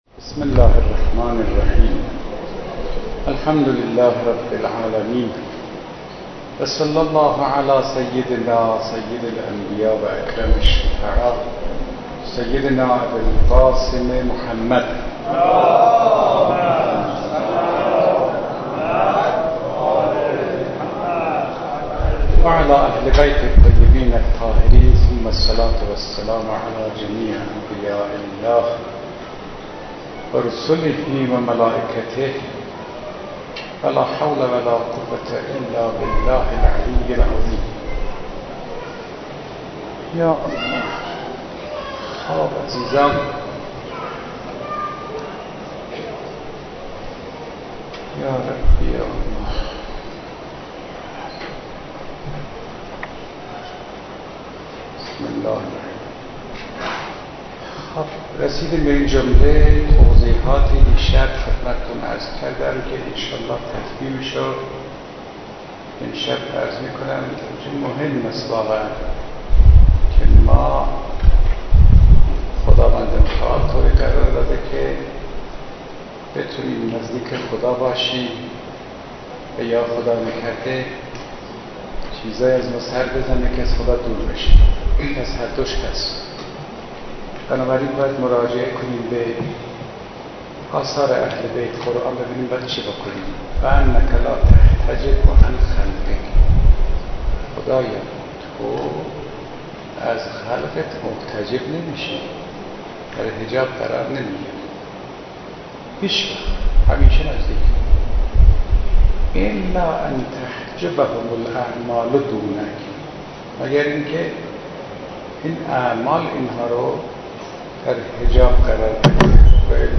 سخنرانی آیت الله فاطمی نیا/ شب سیزدهم/مسجد ازگل کد خبر : ۵۶۹۲۷ گزارش تصویری عقیق: مراسم شب سیزدهم ماه مبارک رمضان با سخنرانی آیت الله فاطمی نیا در مسجد جامع ازگل برگزار شد.